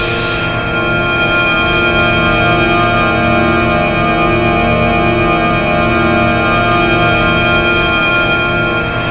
A#3 BOWED06L.wav